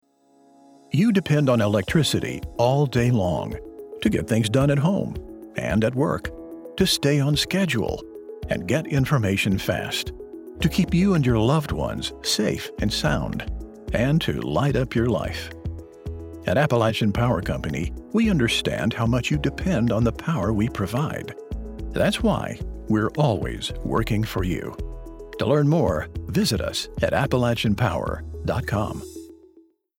Digital Home Studio
Shure KSM 32 Large diaphragm microphone
Radio Portfolio
Appalachian_Power_Radio_2.mp3